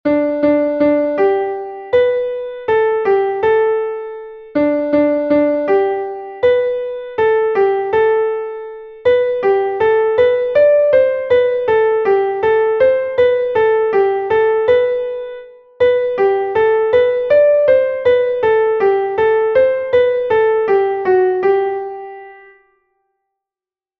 Einstimmige Melodie im Violinschlüssel, G-Dur, 3/4-Takt, mit der 1. Strophe des Liedtextes.
Text und Melodie: Anton Wilhelm von Zuccalmaglio (1803–1869)
kein-schoener-land-in-dieser-zeit_klavier_melodiemeister.mp3